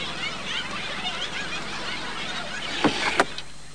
crowd.mp3